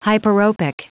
Prononciation du mot hyperopic en anglais (fichier audio)
Prononciation du mot : hyperopic